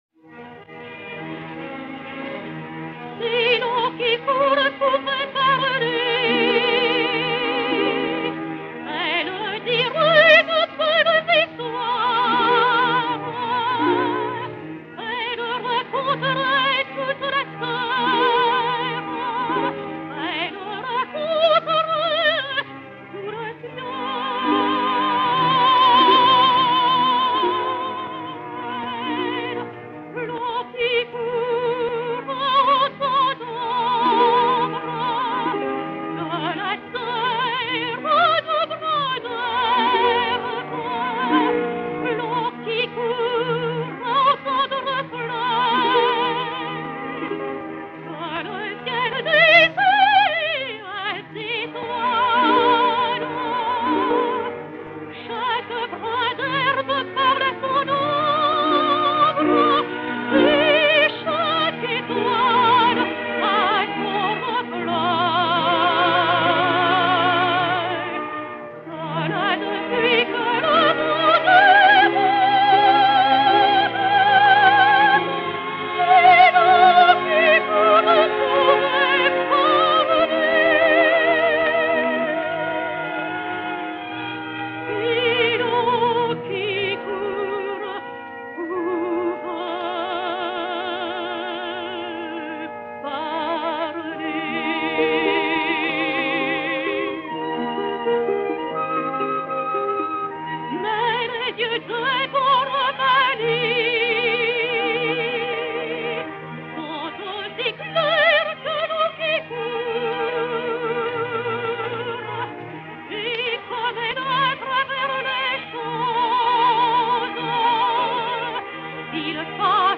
Yvonne Gall et Orchestre
Pathé saphir 80 tours n° 450